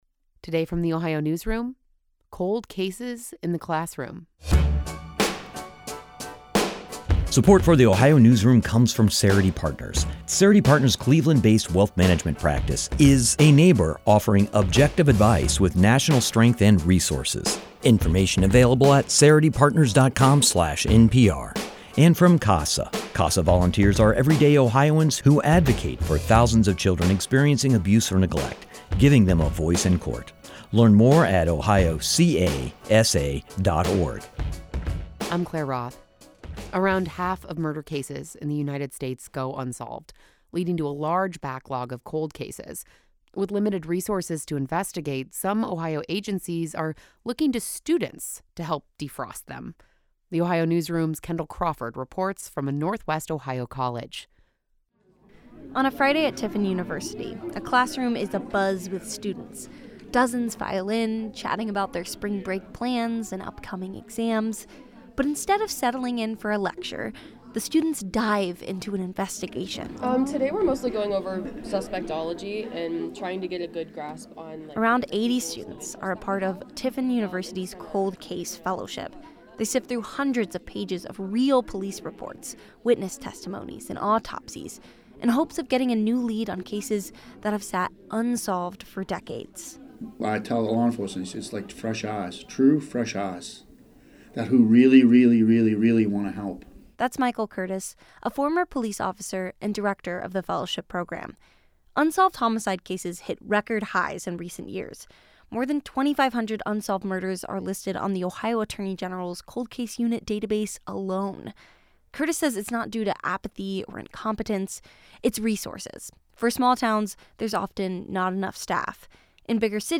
Students at Tiffin University discuss a cold case.
On a Friday at Tiffin University, a classroom is abuzz with students. Dozens file in, chatting about their spring break plans and upcoming exams.